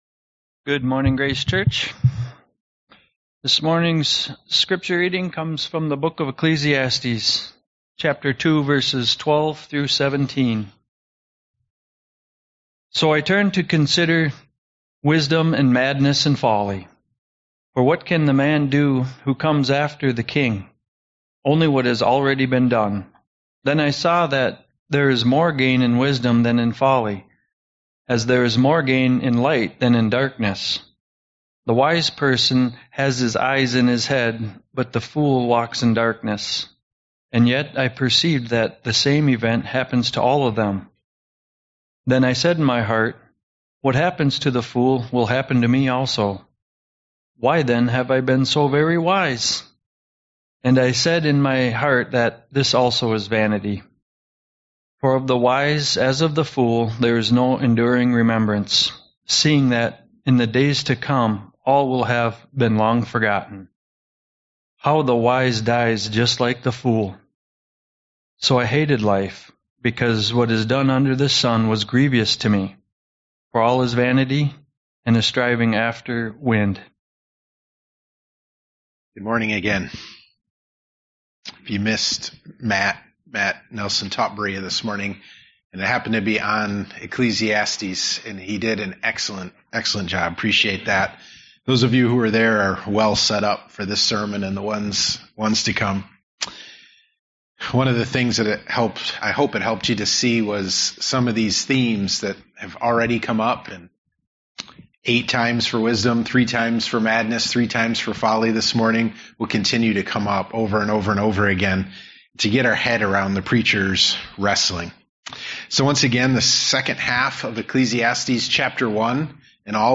Podcasts de Sermons Grace Evangelical Free Church Wyoming